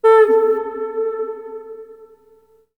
REBKHorn05.wav